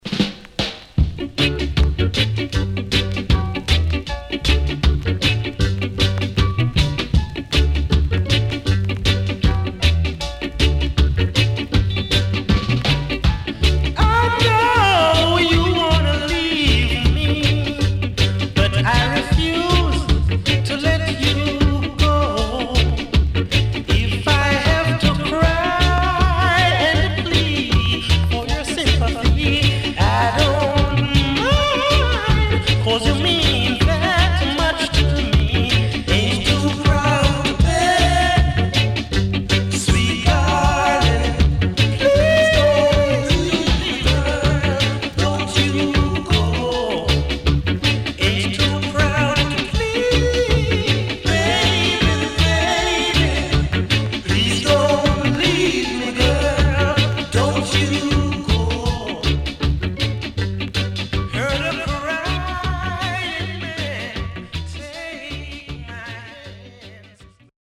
CONDITION SIDE A:VG(OK)
Nice Early Reggae Vocal & Foundation.W-Side Good
SIDE A:所々チリノイズがあり、少しプチノイズ入ります。